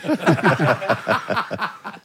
4 guys having a laugh
chortle chuckle giggle haha howl joke laugh laughing sound effect free sound royalty free Funny